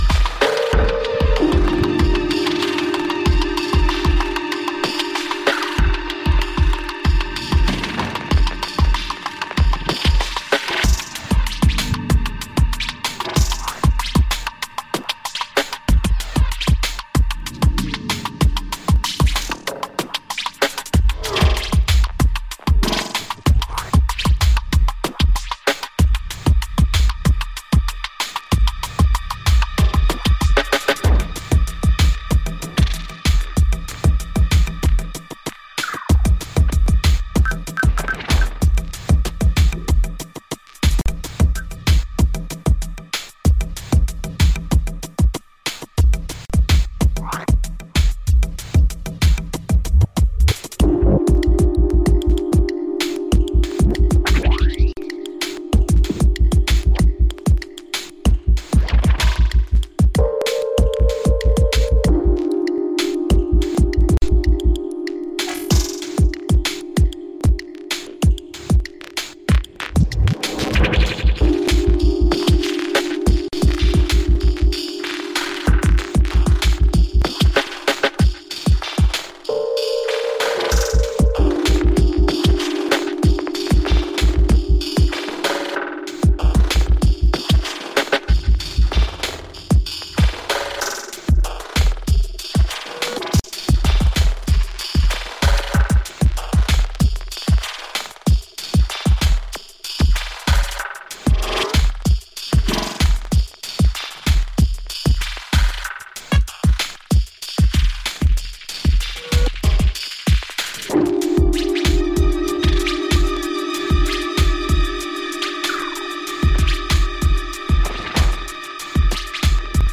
95BPMのデンボウトロニカ